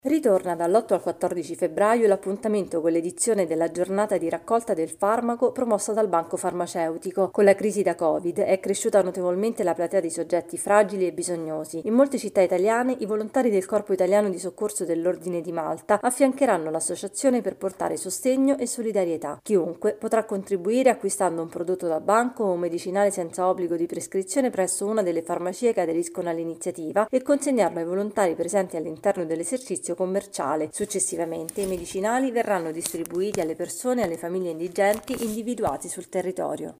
Società